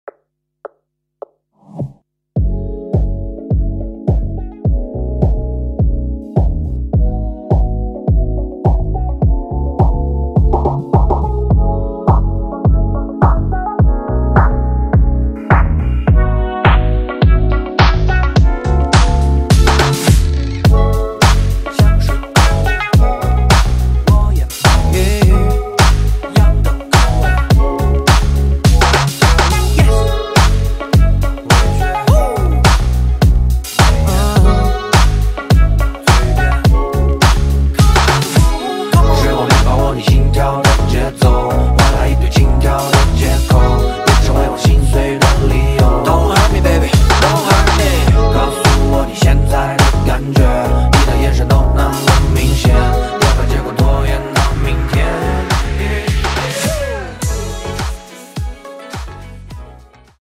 歌曲调式：E大调